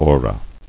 [o·ra]